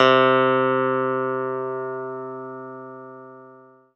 CLAVI1.04.wav